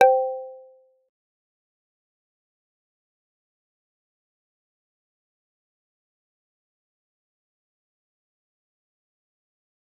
G_Kalimba-C5-mf.wav